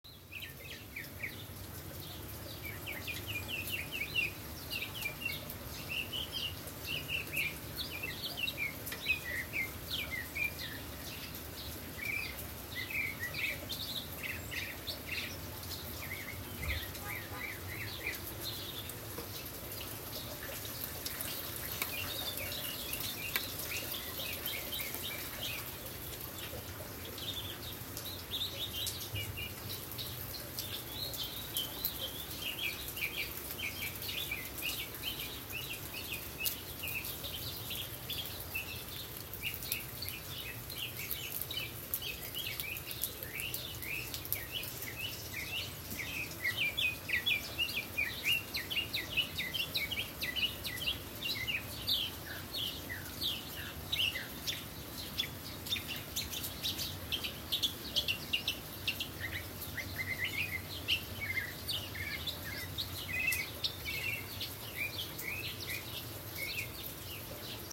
Birdsong in rain .m4a
Awesome sound layering!
I don’t think there are many sounds more beautiful than birdsong in the rain.
You taught me to record the garden.